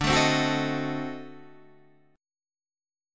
D7b9 chord